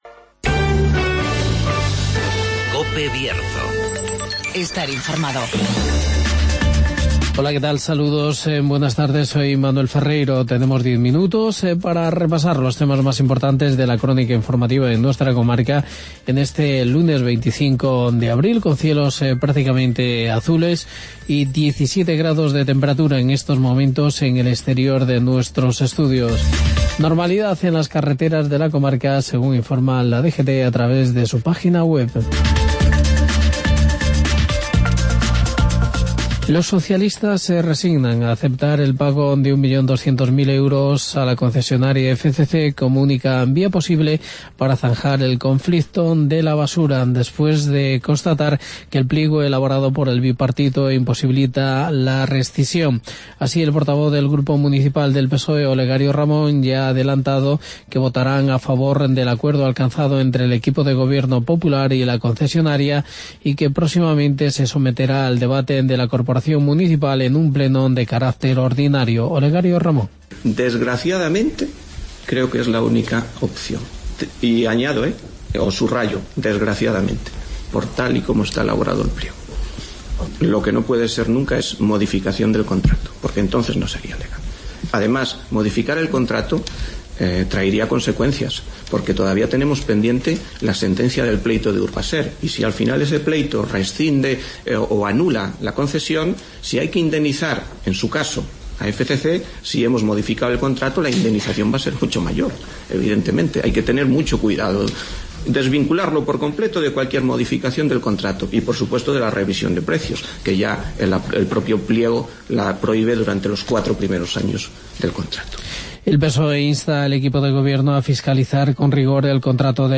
AUDIO: Informativo Mediodía Cope Bierzo, lunes 25 de abril de 2016